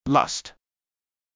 lust 欲望（特に性的） /lʌst/ Lで始まる、感情・欲求に関する話題